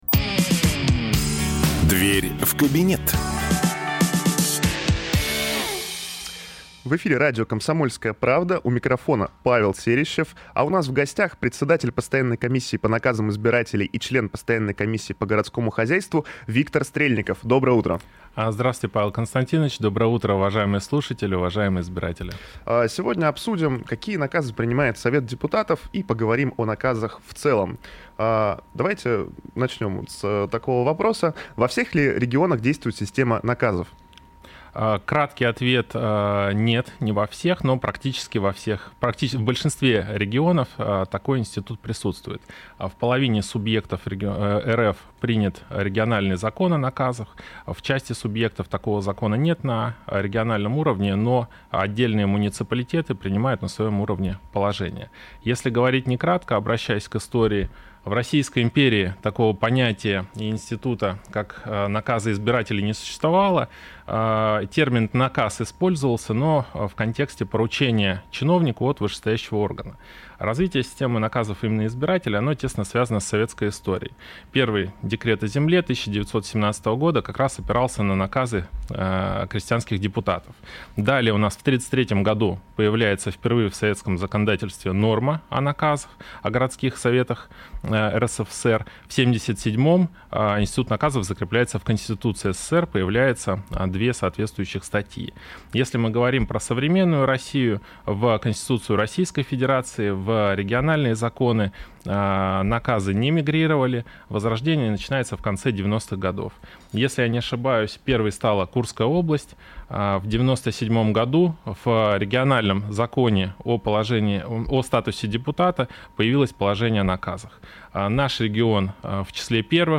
Запись программы, транслированной радио "Комсомольская правда" 14 апреля 2026 года Дата: 14.04.2026 Источник информации: радио "Комсомольская правда" Упомянутые депутаты: Стрельников Виктор Александрович Аудио: Загрузить